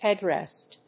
音節héad・rèst 発音記号・読み方
/ˈhɛˌdrɛst(米国英語), ˈheˌdrest(英国英語)/